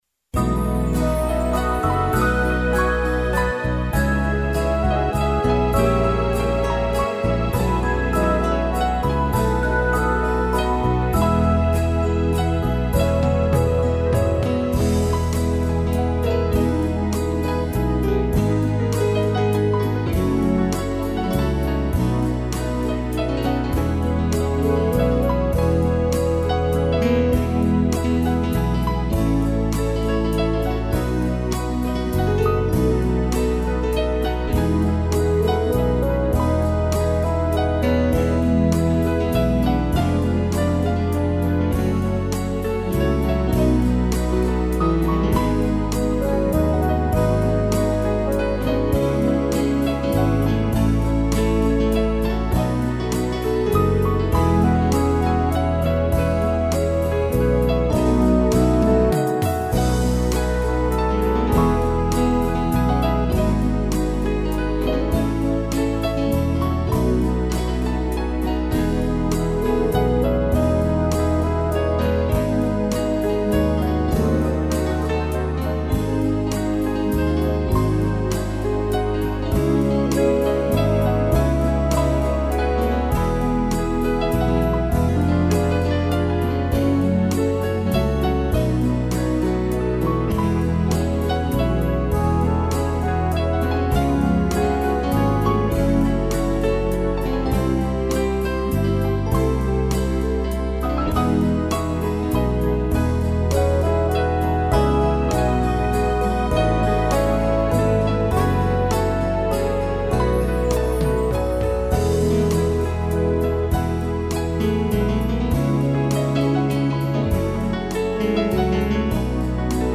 Choir & Pad, fagote e piano
(instrumental)